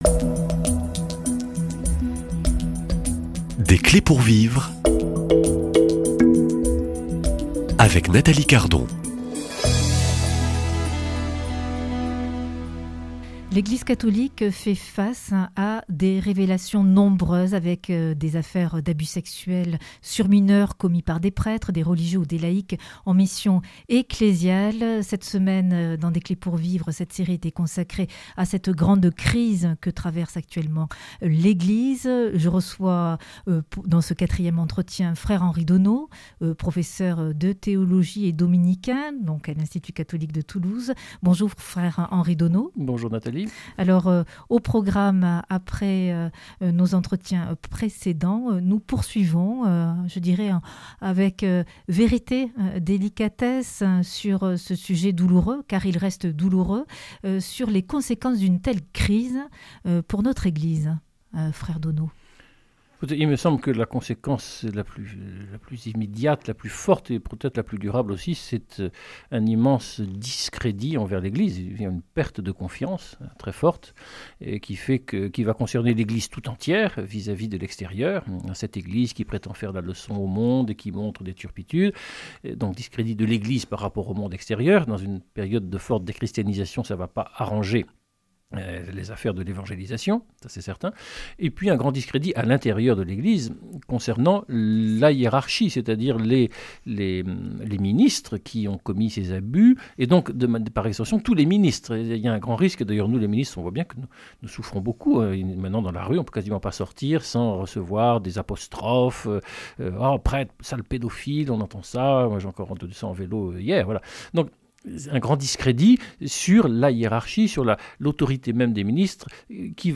Quels sont les conséquences pour l’Église des abus sexuels ? Invité